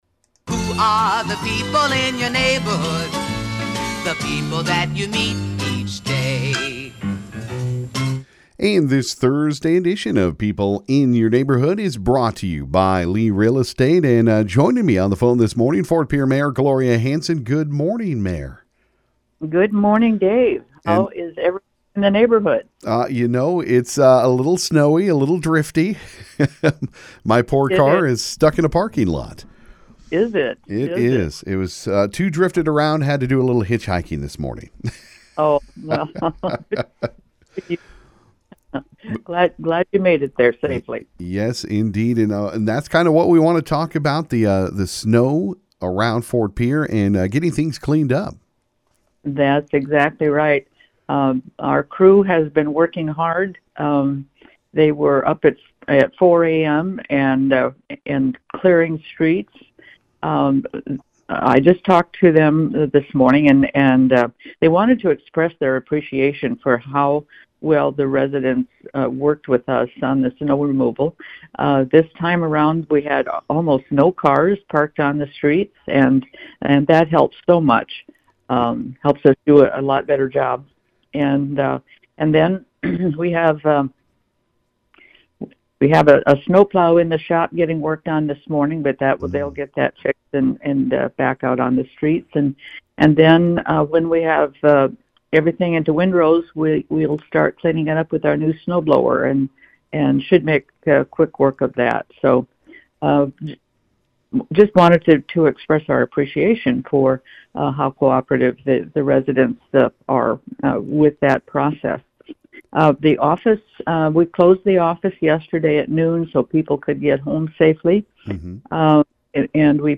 This morning on People In Your Neighborhood on KGFX the Ft. Pierre Mayor called in.